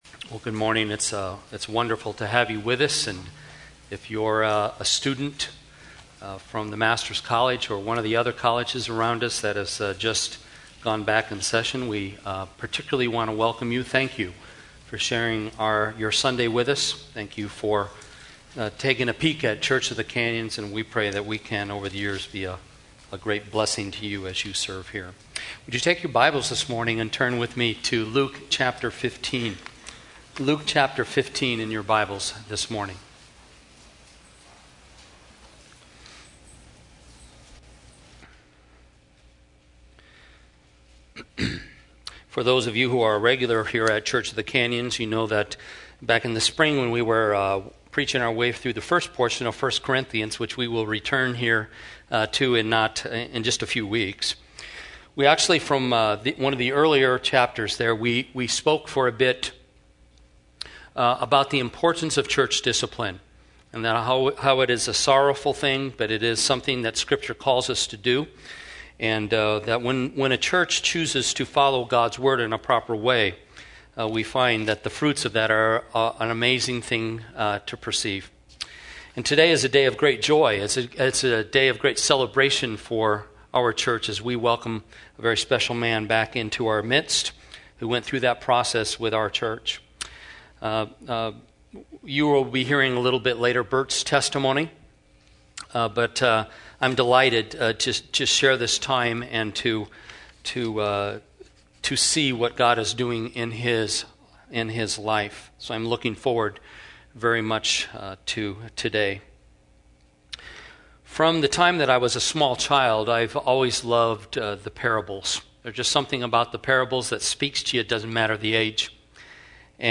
Church of the Canyons - Sermons - Santa Clarita - Evangelical Free